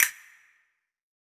9CASTANET.wav